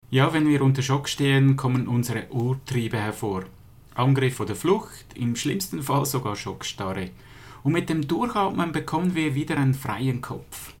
Dieses Interview gibt es auch auf Schwitzerdütsch!